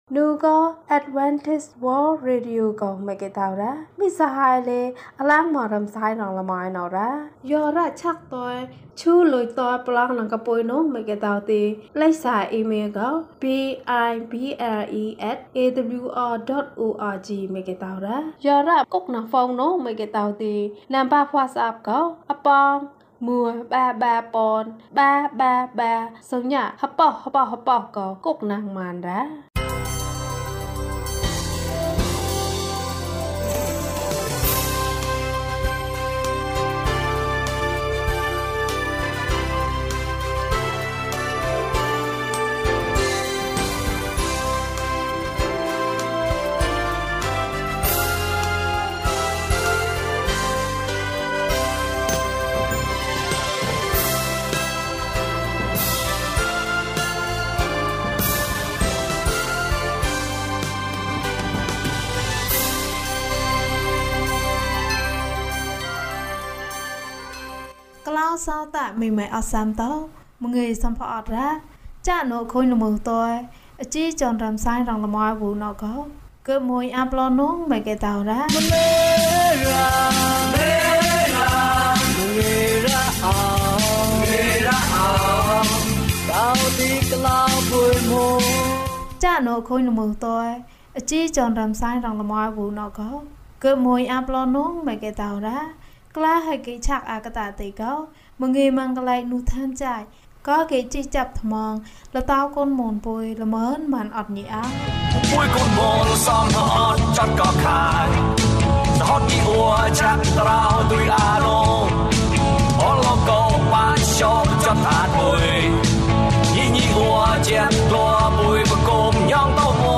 ဘုရားသခင်ကို နေ့စဉ်ကိုးကွယ်ပါ။ ကျန်းမာခြင်းအကြောင်းအရာ။ ဓမ္မသီချင်း။ တရားဒေသနာ။